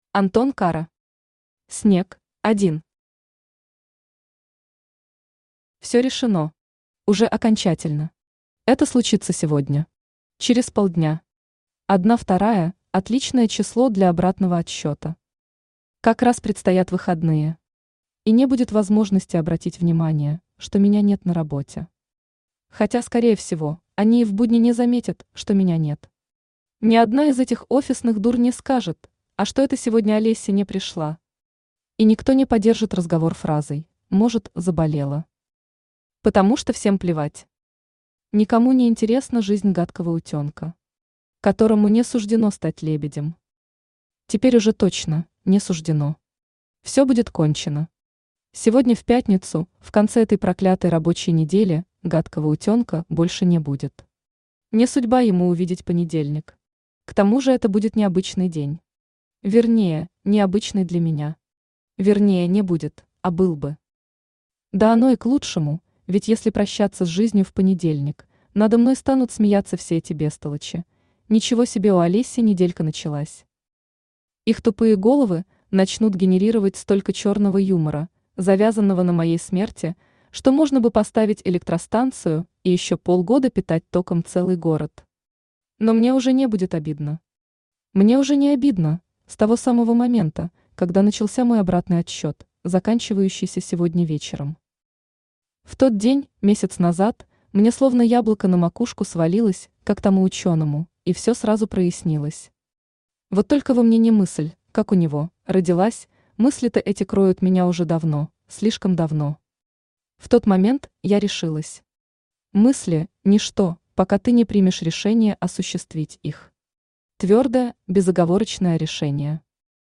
Aудиокнига Снег Автор Антон Кара Читает аудиокнигу Авточтец ЛитРес.